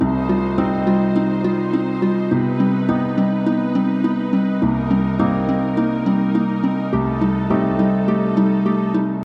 Such A Beautiful, Low-sounding Orchestral Ringtone.